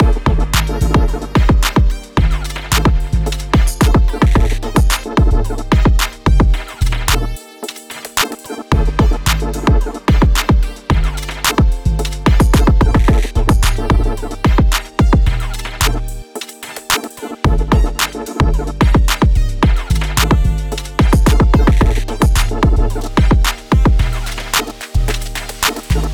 G Minor
Frumpy 808